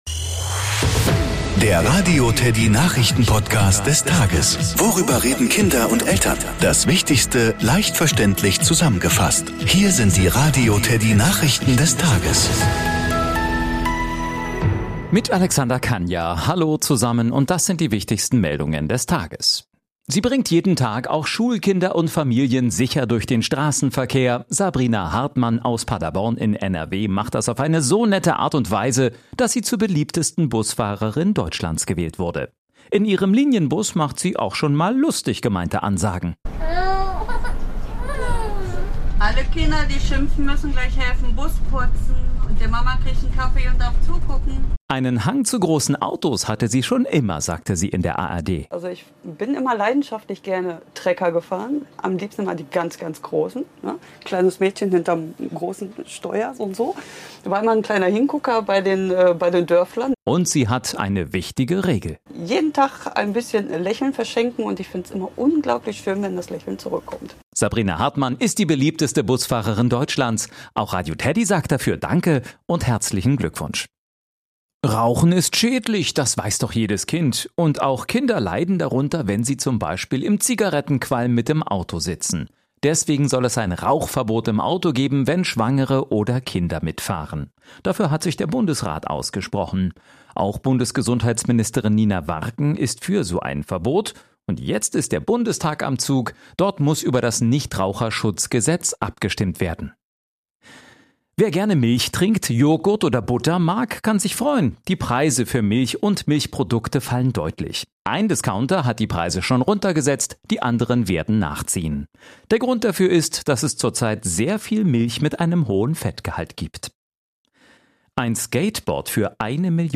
Nachrichten , Kinder & Familie